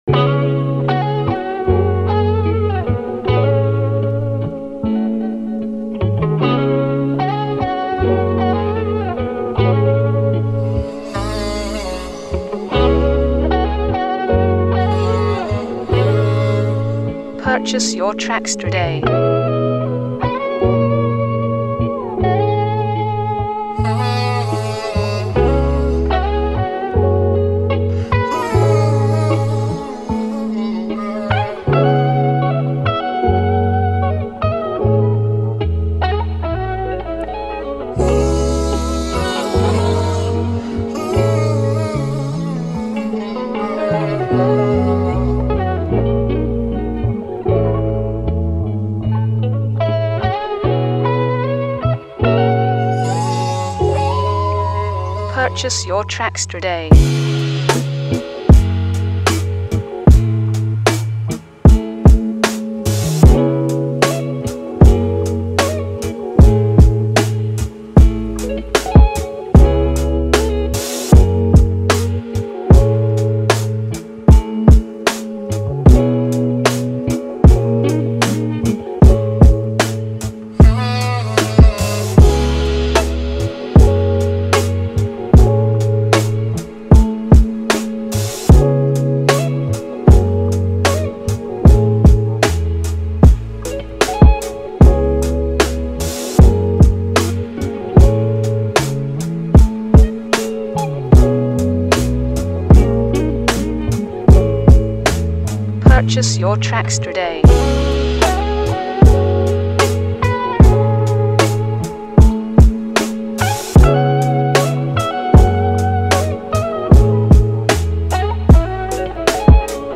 Trap Soul Music